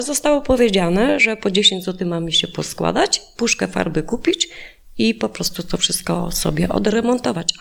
Lokatorka kamienicy.mp3